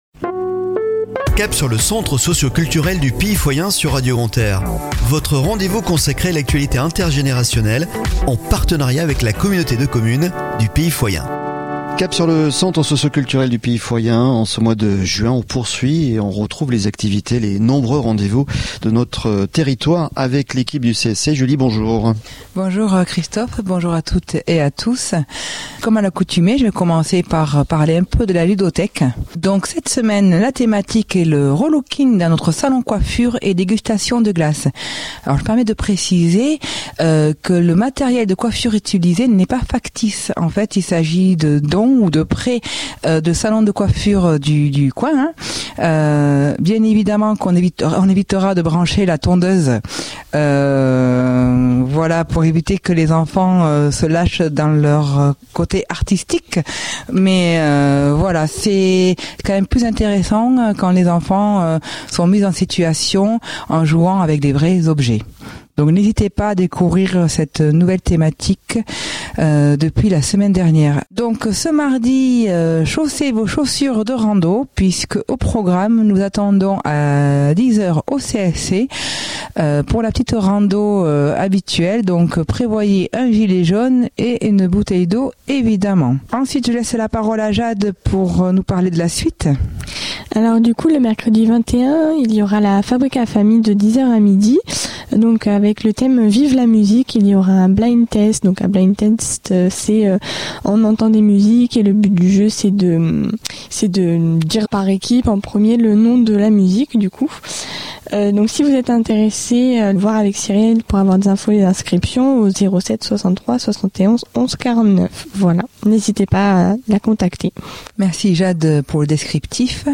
Chronique de la semaine du 19 au 25 Juin 2023 !